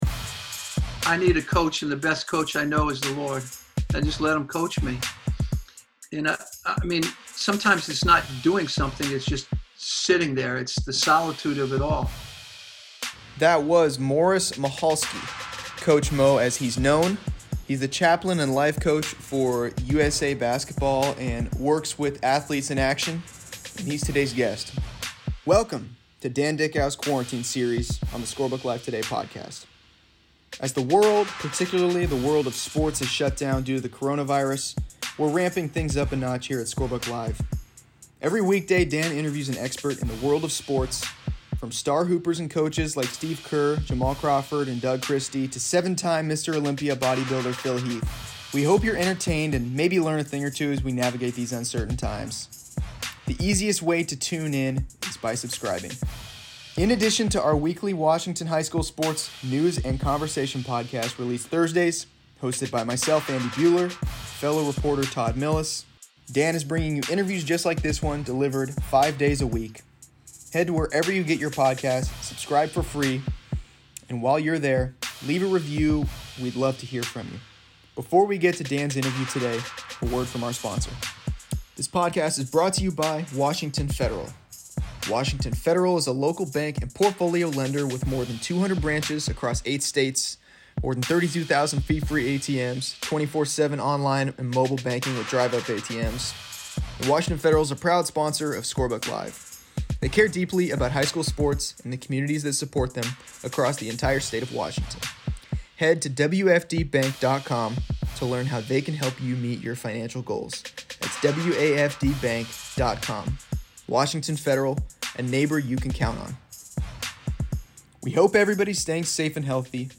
Each weekday, Dickau releases an interview with a wide range of experts in the world of sports.